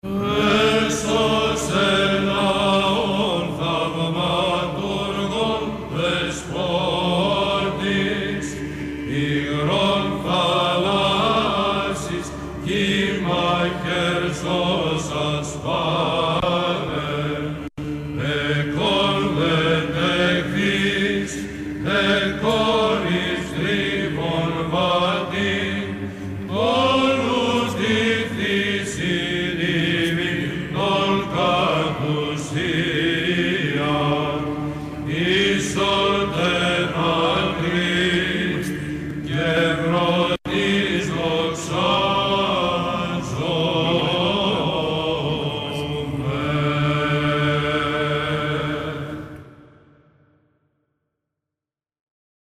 ψάλλει ο λαμπαδάριος
Ψαλλεται σε πανηγυρικό πρώτο ήχο στον Ορθρο της εορτής, αμέσως μετά την πολύ γνωστή Καταβασία «Χριστός γεννάται δοξάσατε…».
Ιαμβικός Κανών.
Ήχος α’. ο Ειρμός.